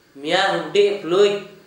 Dialect: Hill